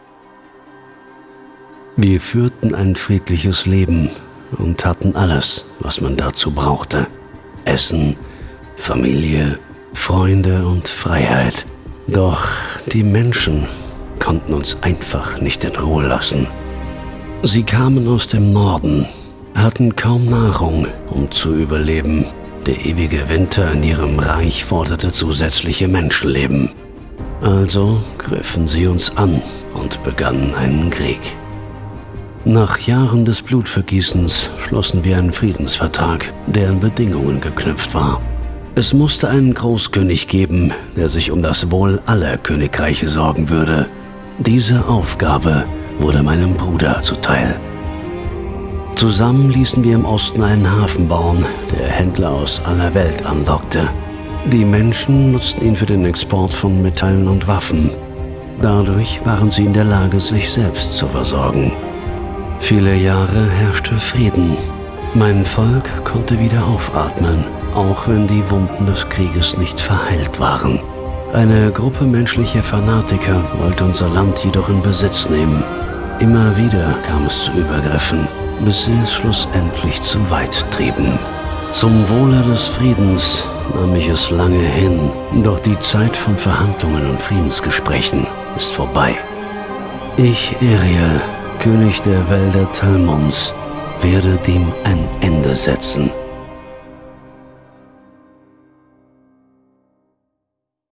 markant, sonore Stimme, kernig Stimmalter: 35-65
Sprechprobe: Sonstiges (Muttersprache):
However, my voice is very versatile, from distinctive, sonorous to cartoon funny.